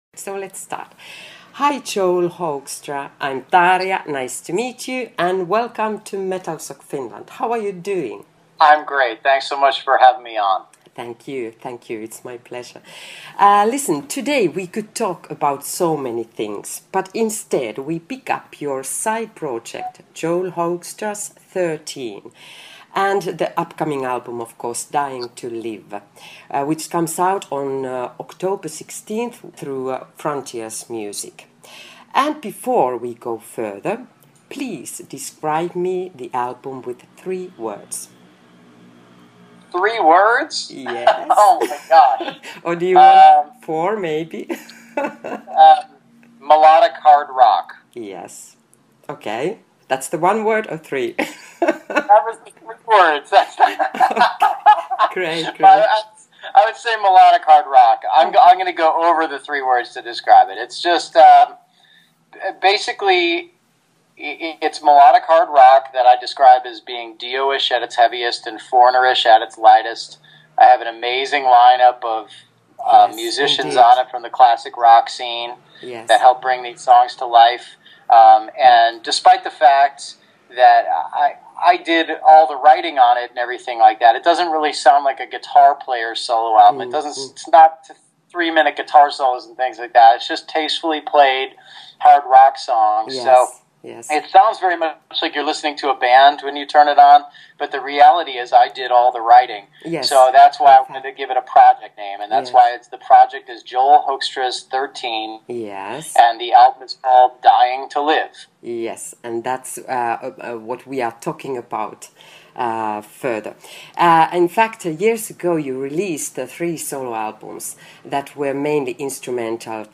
Audio Interview With JOEL HOEKSTRA, Talking About JOEL HOEKSTRA’S 13, Upcoming Album “Dying To Live” And WHITESNAKE
interview-joelhoekstra-7sep2015.mp3